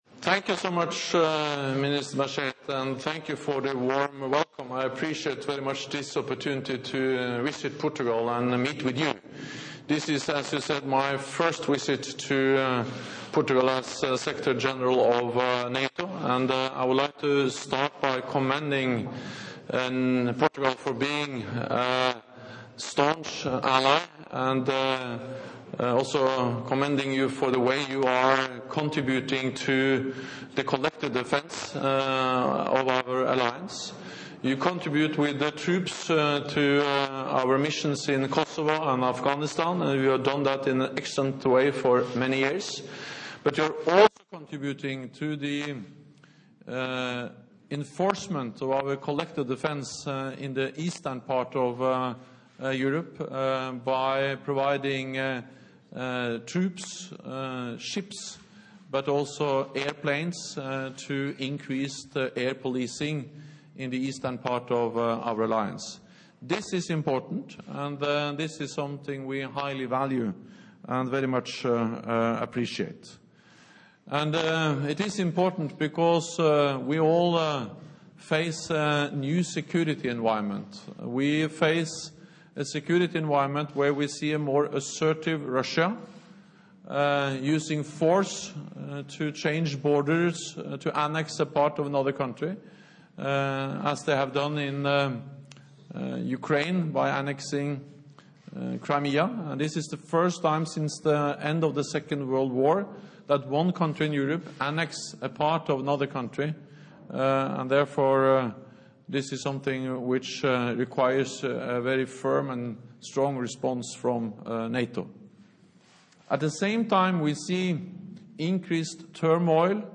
Joint press point with NATO Secretary General Jens Stoltenberg and the Minister of State and Foreign Affairs of Portugal, Rui Machete